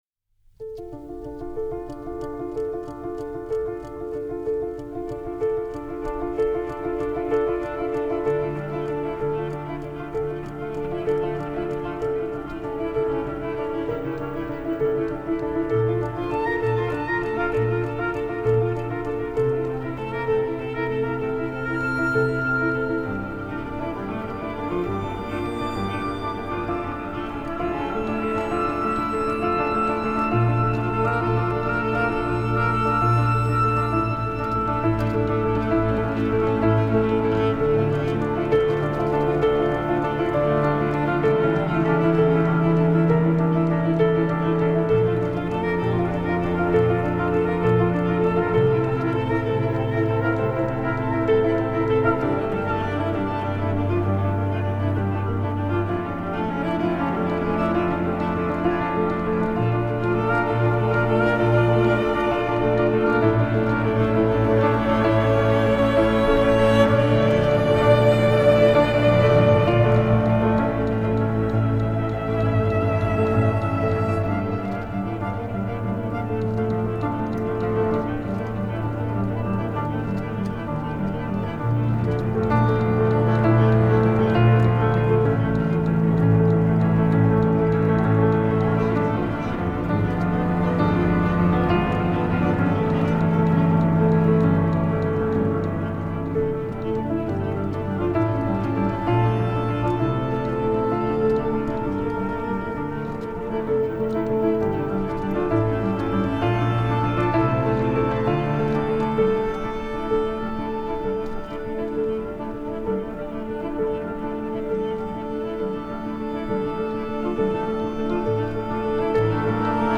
الهام‌بخش , پیانو , کلاسیک , موسیقی بی کلام , ویولن